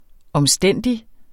Udtale [ ʌmˈsdεnˀdi ]